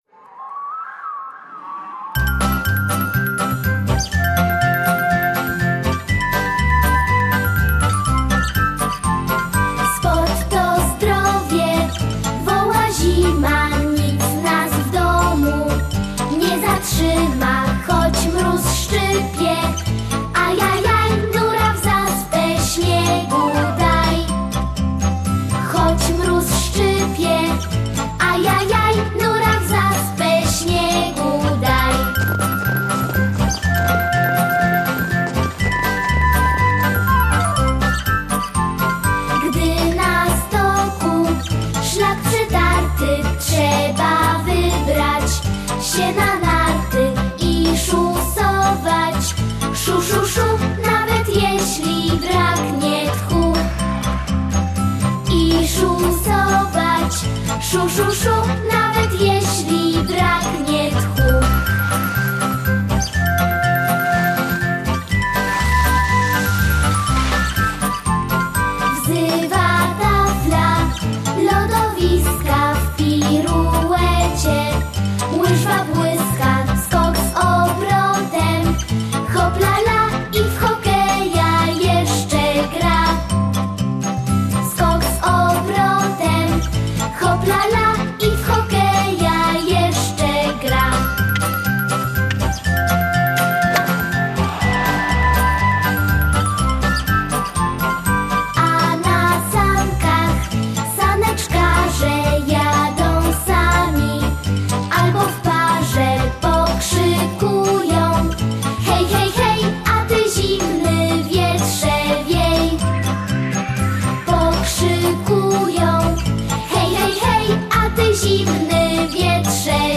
Zaśpiewajcie piosenkę „Sport to zdrowie”